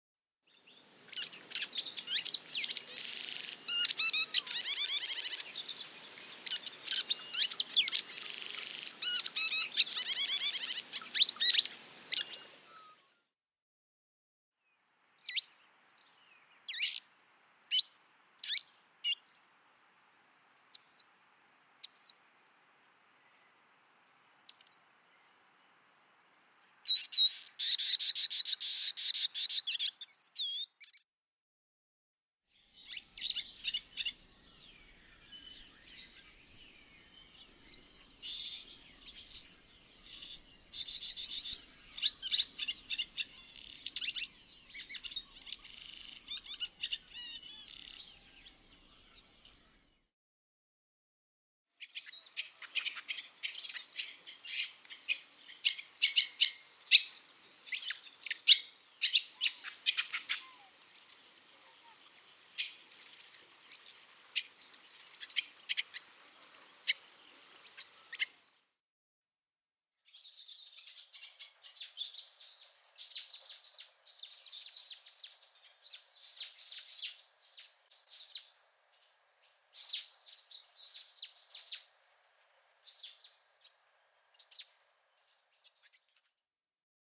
Welcome Swallow
Hirundo neoxena
Songs & Calls
welcome-swallow-web.mp3